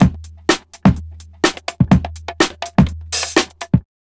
GRV125LOOP-L.wav